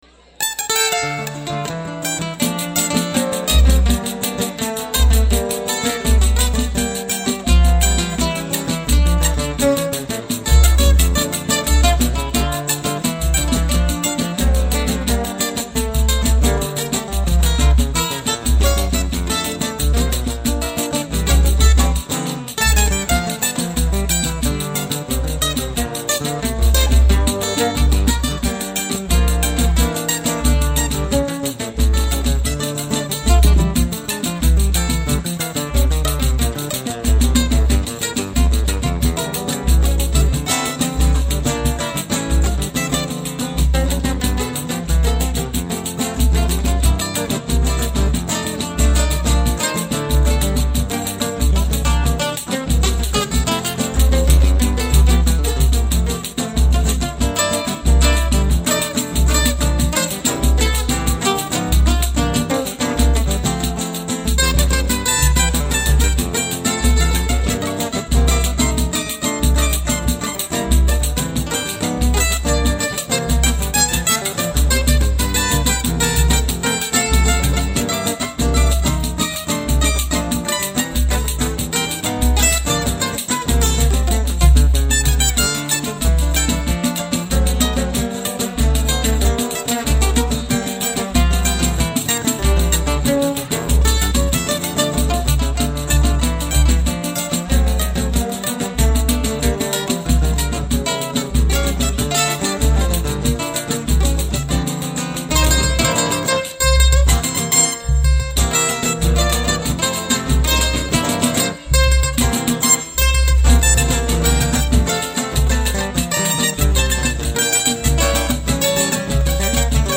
Chorinho